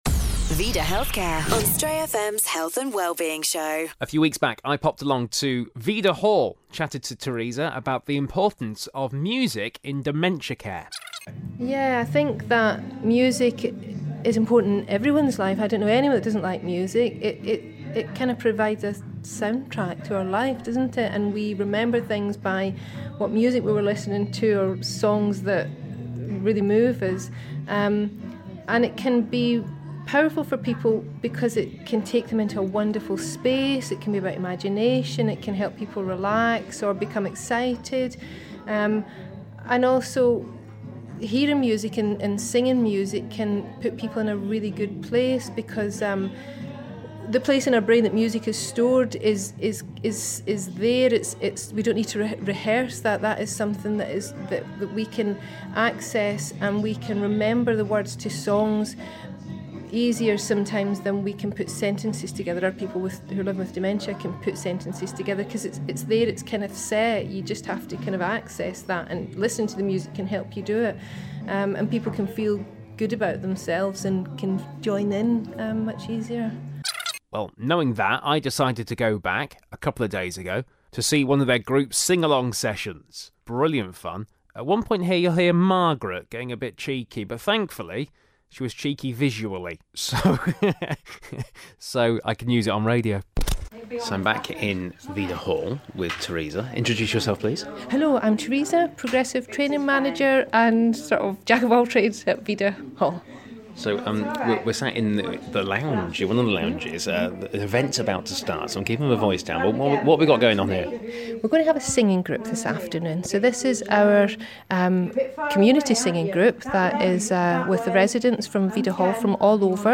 Sing-along session at VIDA Healthcare